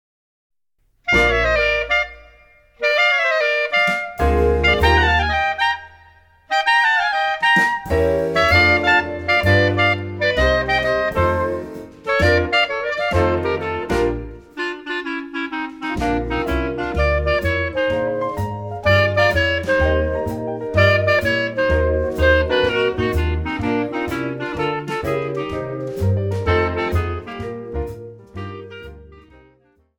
Vibraphone
Piano
Bass
Drums
Guitar